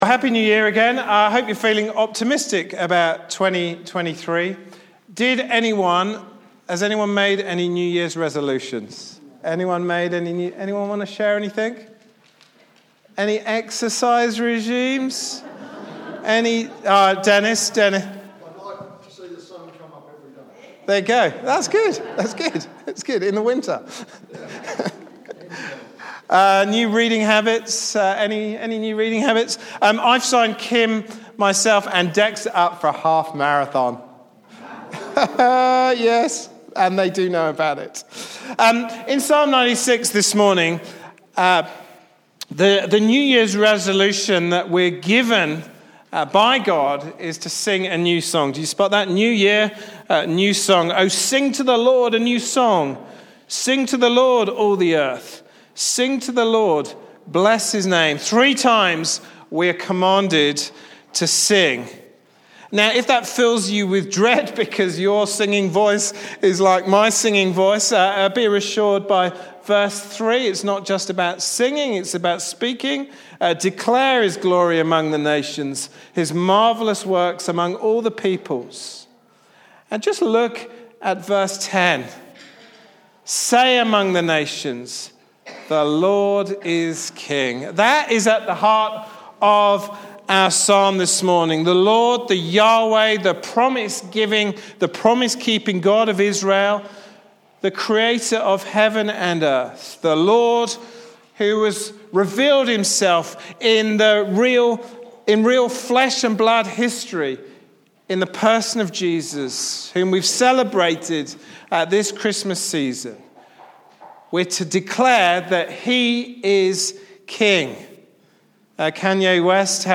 Psalm 96 Service Type: Sunday morning service « Acts 29 Psalm 130